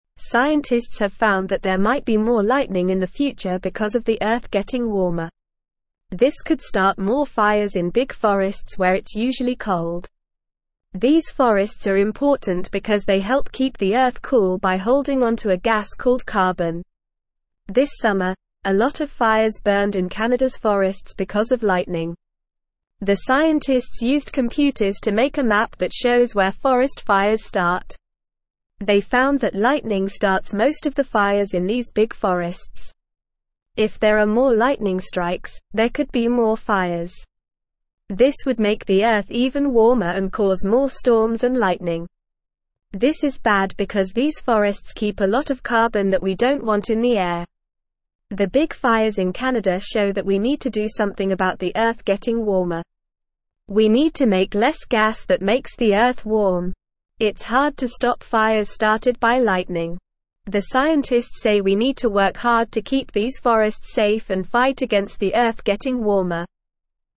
Normal
English-Newsroom-Lower-Intermediate-NORMAL-Reading-More-Lightning-and-Fires-in-Cold-Woods.mp3